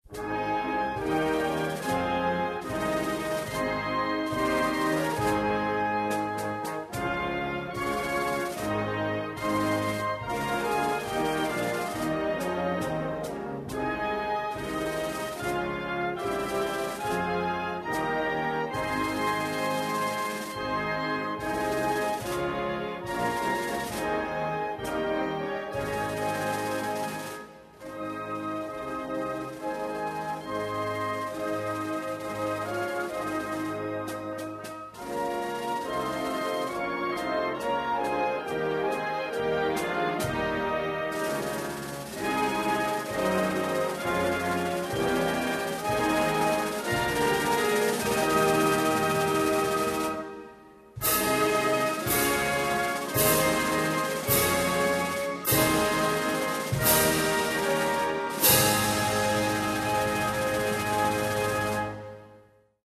Na spletu poišči himne Avstralije, Nove Zelandije in Francoske Polinezije.